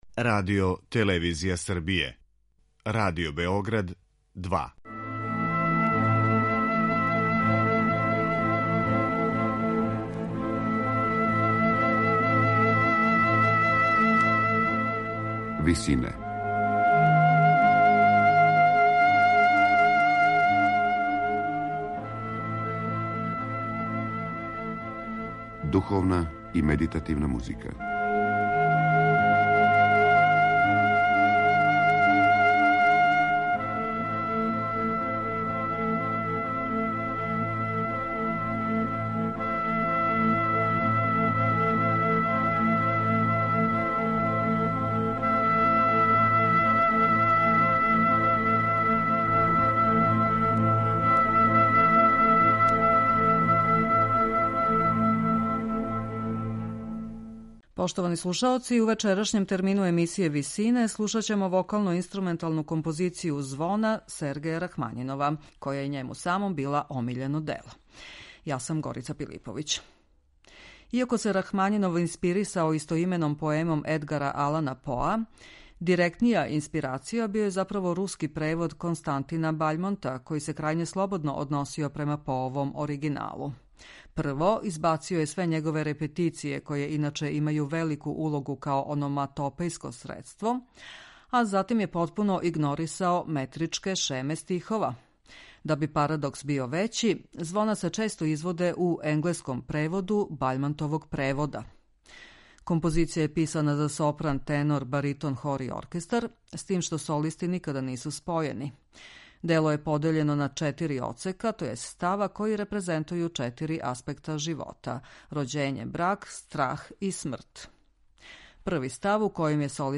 У вечерашњем термину емисије Висине, слушаћемо вокално-инструменталну композицију Звона Сергеја Рахмањинова.
Композиција је писана за сопран, тенор, баритон, хор и оркестар, с тим што солисти никада нису спојени. Дело је подељено на четири одсека, тј. става који репрезентују четири аспекта живота - рођење, брак, страх и смрт.